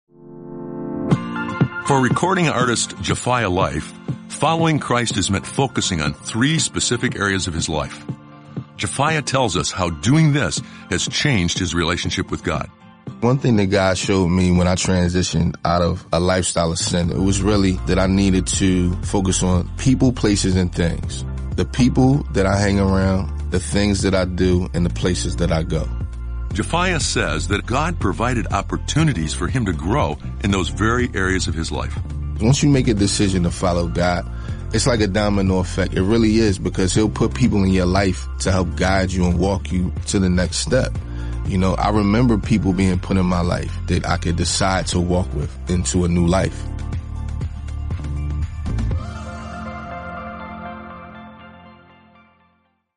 Each day, you’ll hear a short audio message with simple ideas to help you grow in your faith.